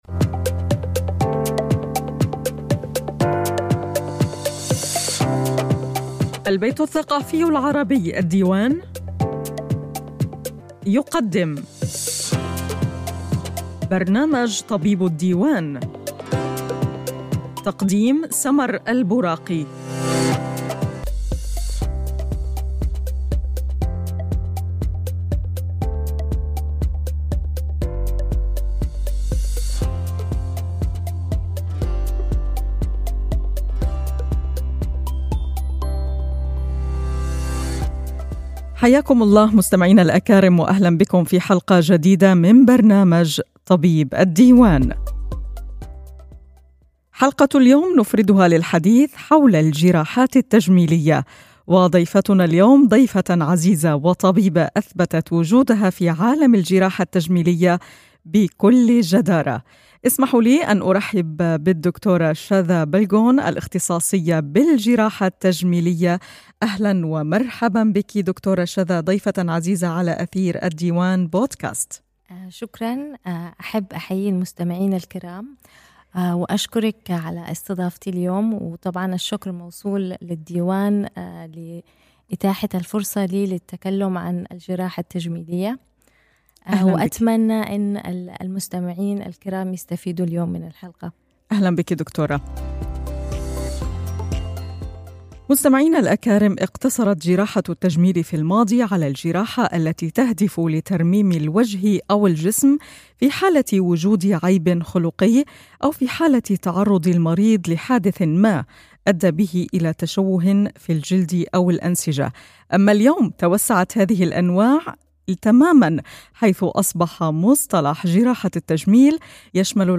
Interessante und nützliche Tipps sollen zu verschiedenen medizinischen Themen besprochen werden. In dieser Podcast-Reihe werden Ärzte aus den unterschiedlichen Fachrichtungen bei wöchentlichen Treffen interviewt.